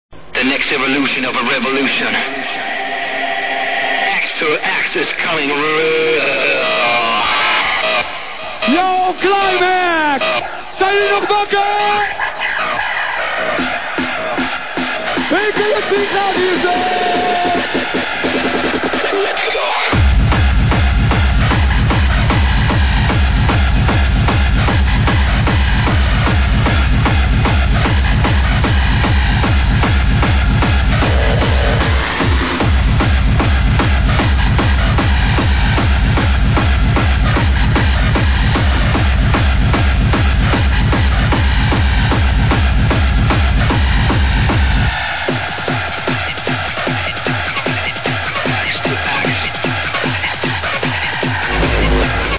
Smile Hardstyle Tune ID
hardstyle track